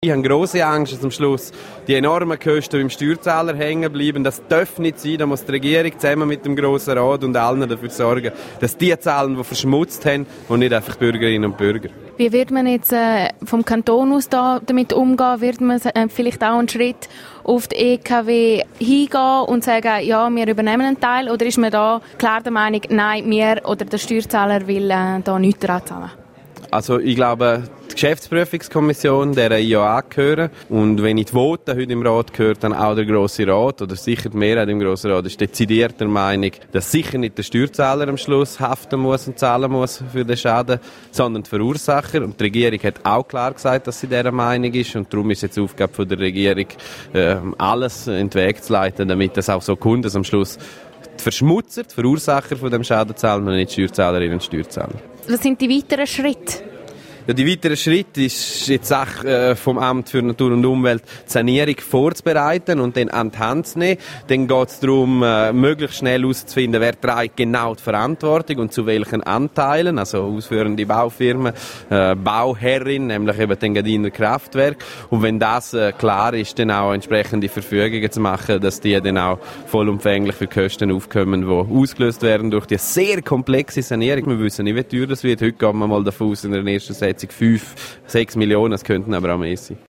Wer schlussendlich die Kosten der Sanierung trägt, bleibt jedoch offen. Radio Südostschweiz hat mit SP-Grossrat Jon Pult darüber gesprochen.
1250 Interview Jon Pult Spöl.MP3